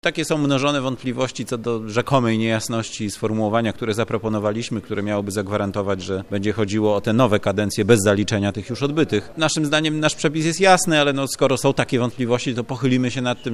Słowom opozycji zaprzeczył wiceprzewodniczący komisji – Marcin Horała z Prawa i Sprawiedliwości. Jak mówił, żadne dodatkowe zmiany w sposobie wyboru wójtów i prezydentów nie są przygotowywane.